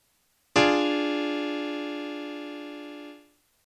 Dreiklang_O.mp3